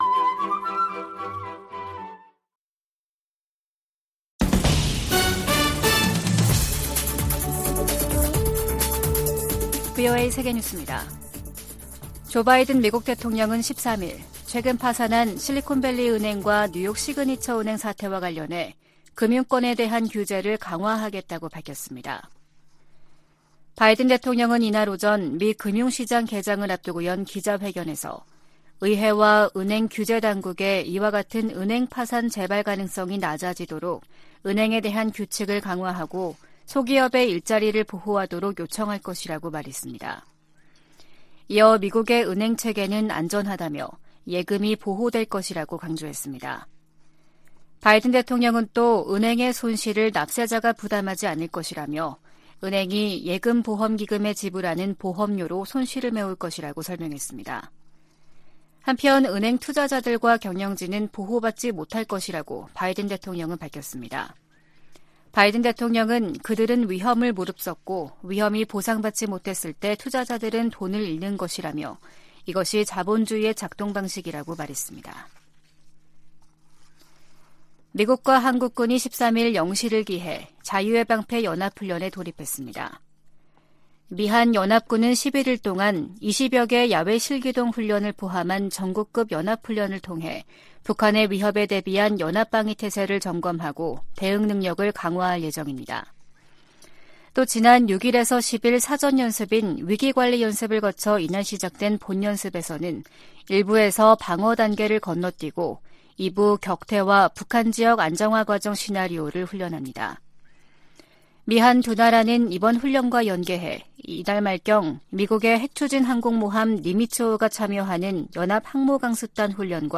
VOA 한국어 아침 뉴스 프로그램 '워싱턴 뉴스 광장' 2023년 3월 14일 방송입니다. 북한이 12일 전략순항미사일 수중발사훈련을 실시했다고 다음날 대외 관영 매체들이 보도했습니다. 미국과 한국은 ‘자유의 방패’ 연합연습을 시작했습니다. 미 국무부가 북한의 최근 단거리 탄도미사일 발사를 규탄하며 대화 복귀를 촉구했습니다.